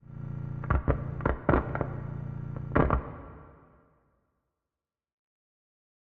Minecraft Version Minecraft Version latest Latest Release | Latest Snapshot latest / assets / minecraft / sounds / ambient / nether / warped_forest / mood6.ogg Compare With Compare With Latest Release | Latest Snapshot